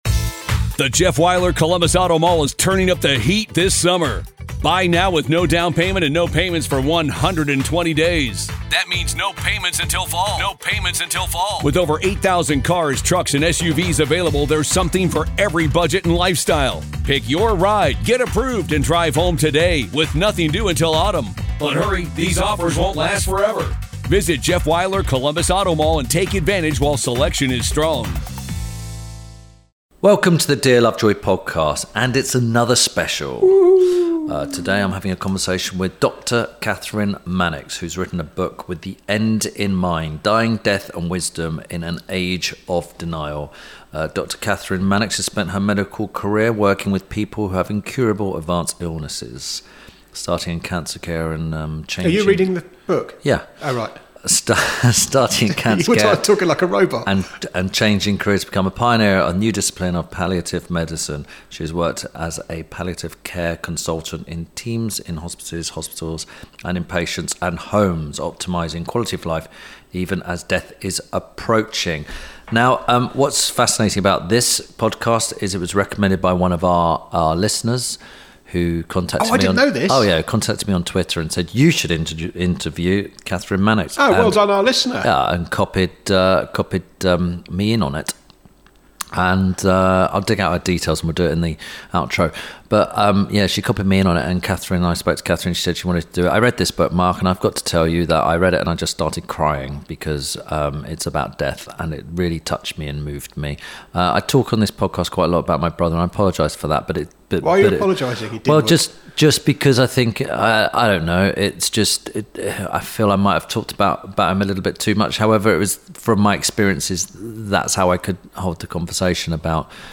INTERVIEW SPECIAL